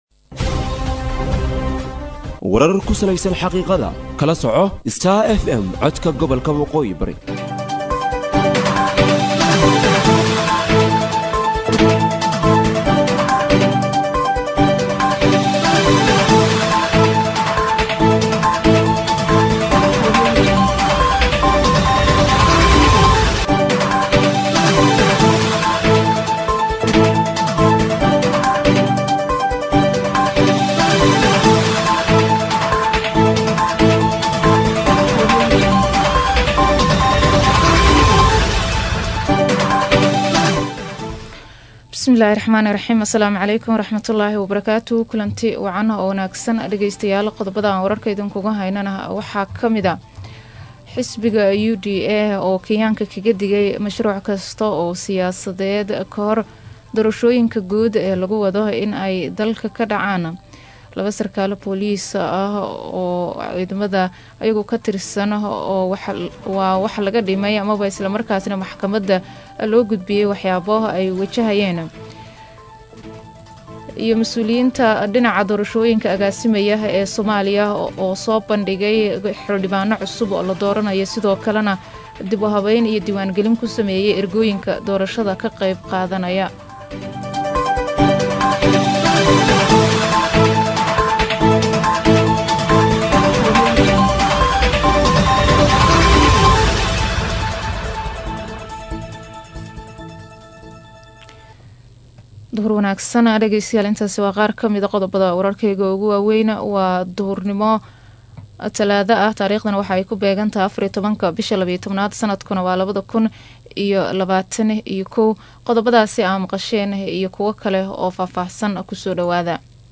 DHAGEYSO:DHAGEYSO:WARKA DUHURNIMO EE IDAACADDA STAR FM